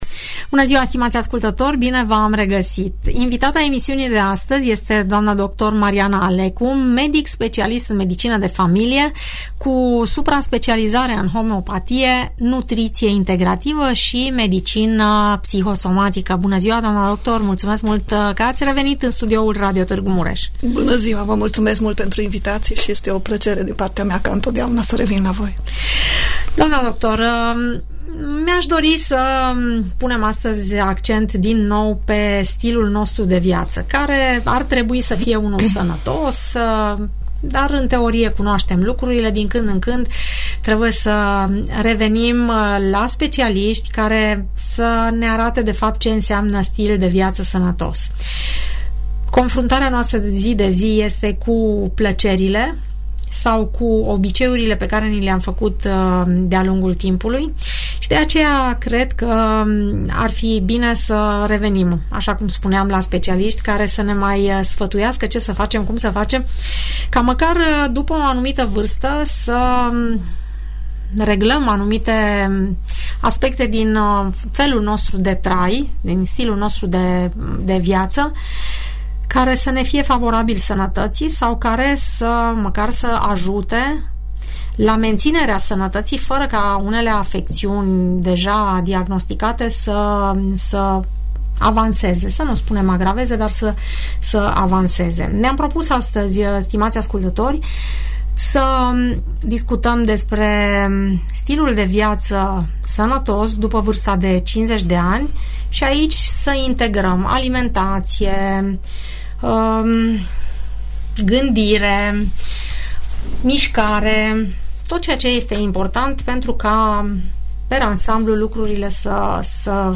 dialogul